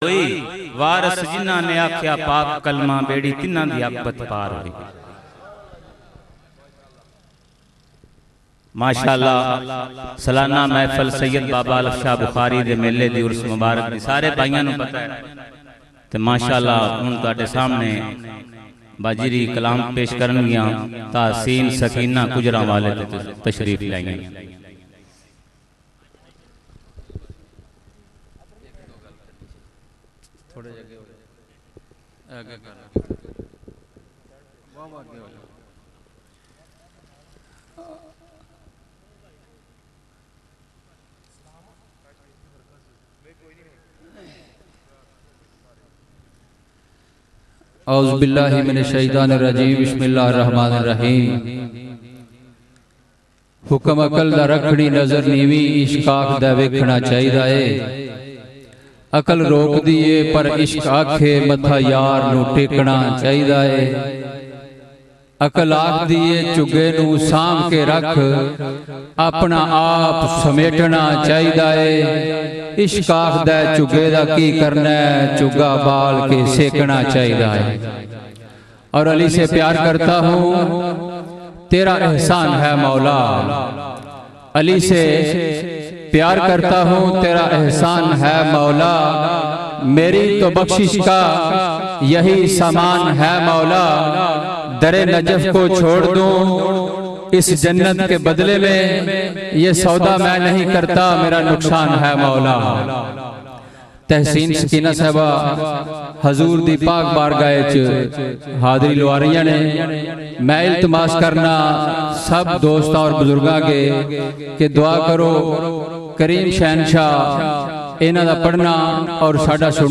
Sufi Songs
Kalaam/Poetry